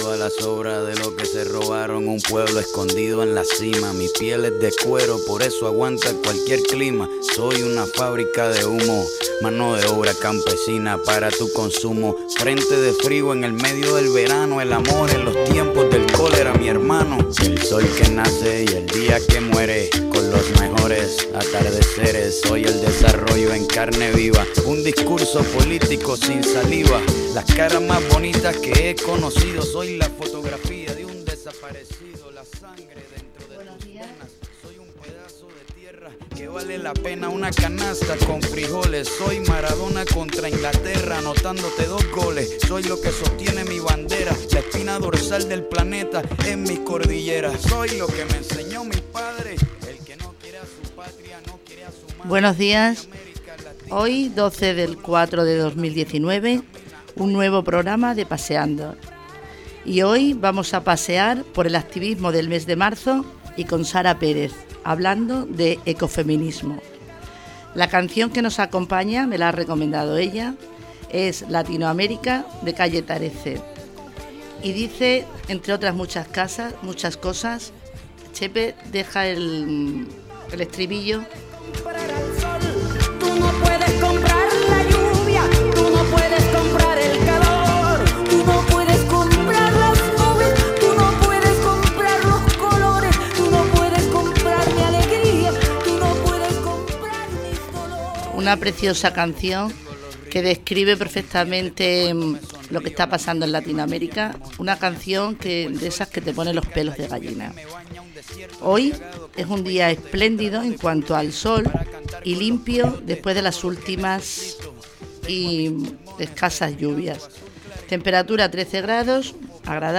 Programa